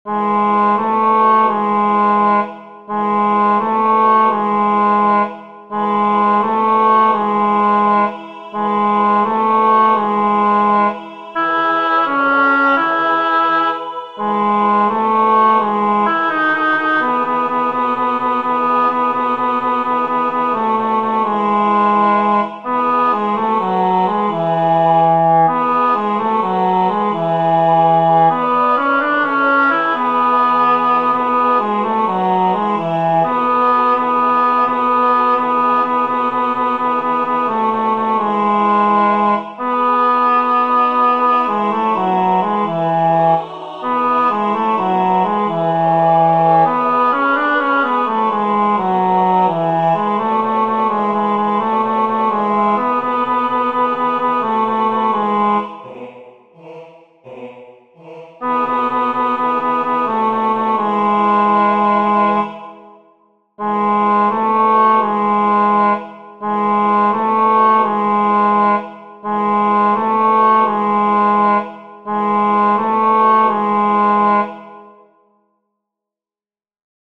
El tempo está marcado como “Allegro”, lo cual sería aproximadamente negra puntillo=120.
Para aprender la melodía os dejo estos MIDIS, con la voz principal destacada por encima del resto.
ya-viene-la-vieja-tenor.mp3